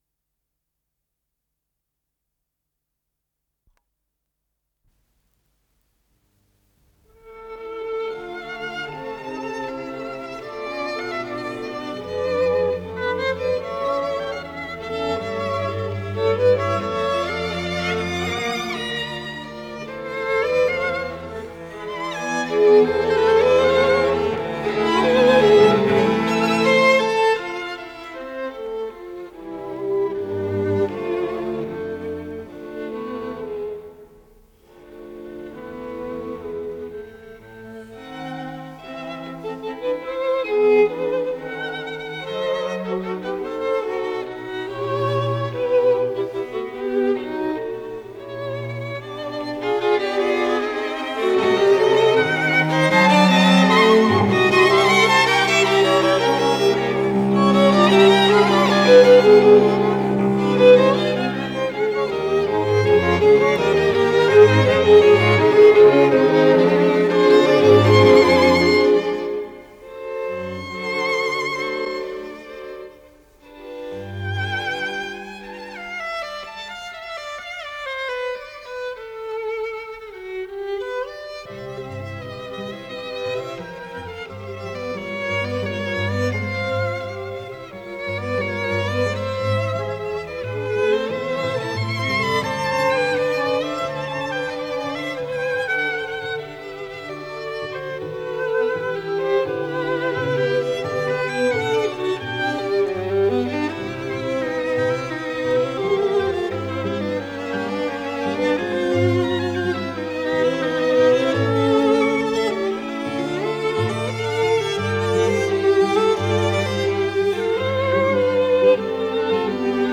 Исполнитель: Струнный квартет
для двух скрипок, альта и виолончели
Ля минор